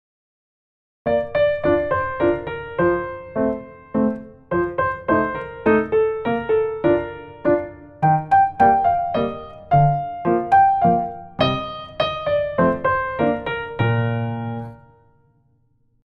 7級B/変ロ長調３拍子
３ 実際に多い演奏例
7級の即興を練習している人だとこんな感じになります。
変奏３.mp3